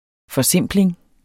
Udtale [ fʌˈsemˀbleŋ ]